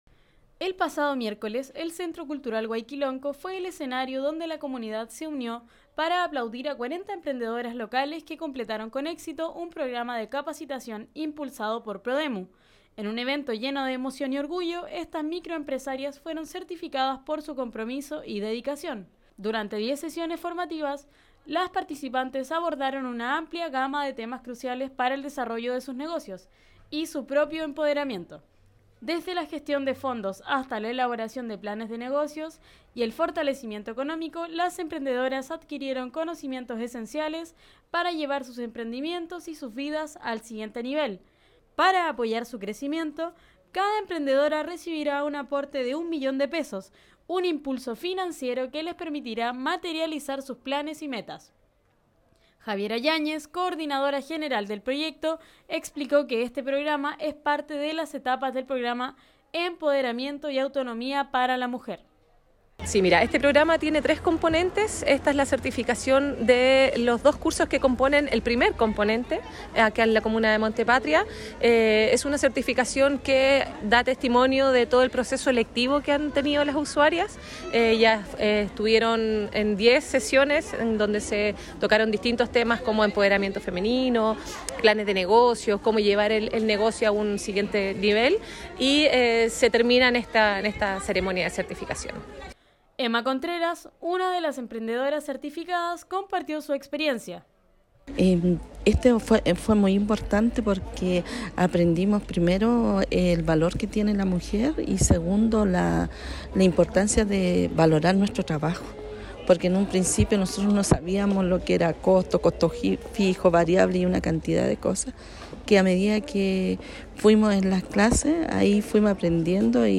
El pasado miércoles, el Centro Cultural Huayquilonko fue el escenario donde la comunidad se unió para aplaudir a 40 emprendedoras locales que completaron conexito un programa de capacitación impulsado por PRODEMU.
DESPACHO-CERTIFICACIOiN-PRODEMU-EMPRENDEDORAS-MONTE-PATRIA.mp3